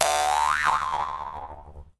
增加撑杆跳音效
polevaultjump.ogg